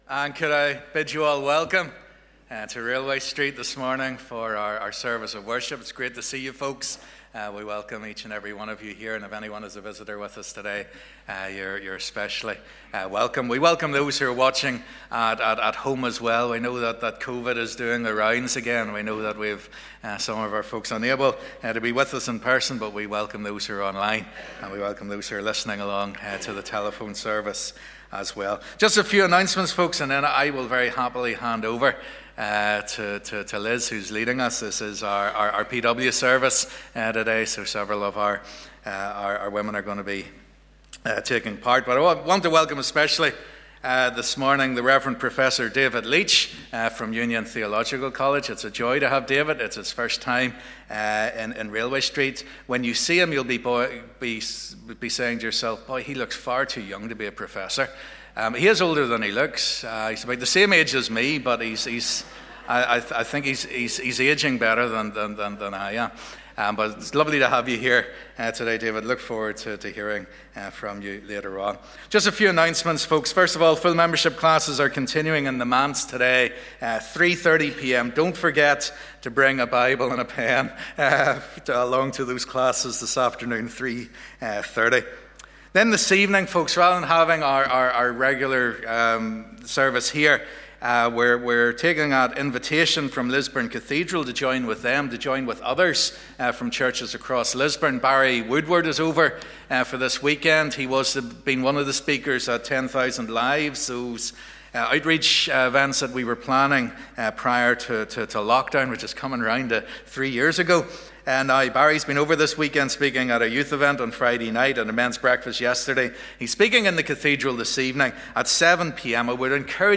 Join us for our annual PW service.
Morning Service